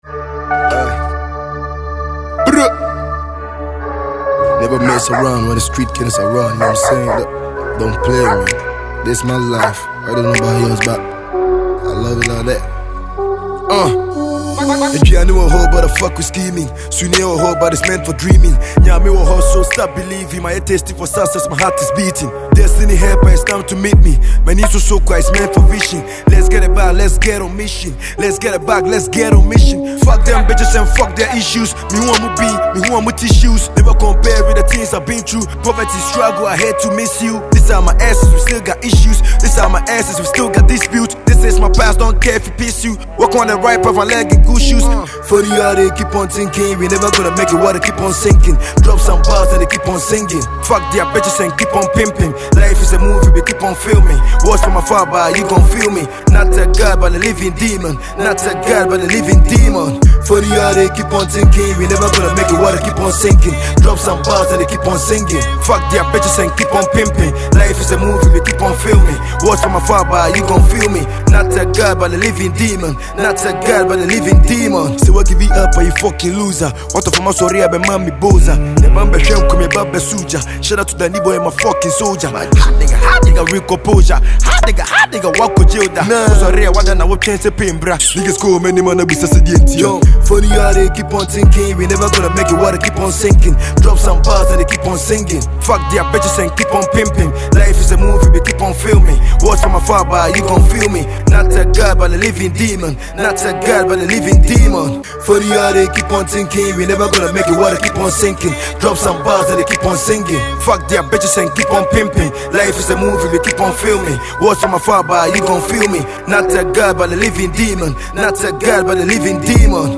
Ghanaian drill sensation
tough Asakaa anthem